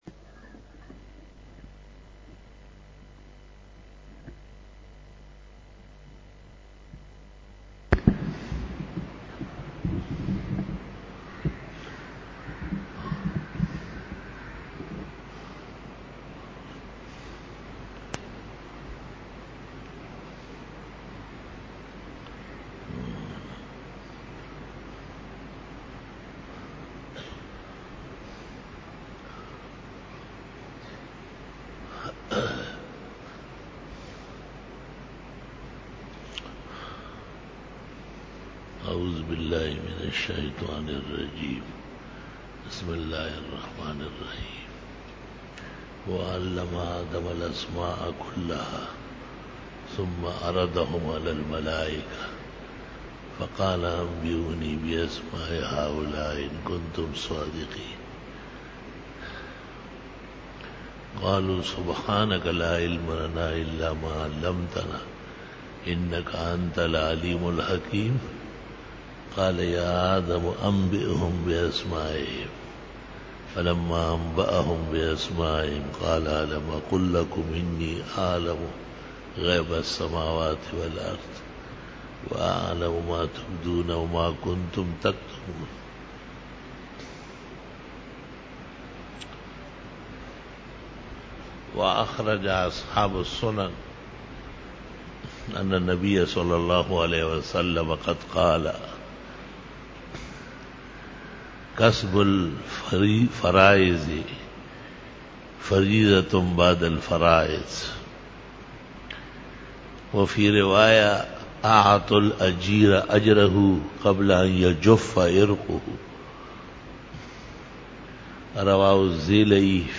18_BAYAN E JUMA TUL MUBARAK (01MAY-2015) (11 Rajab 1436h)
Khitab-e-Jummah 2015